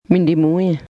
Some long vowels are pronounced with air flowing through the nose.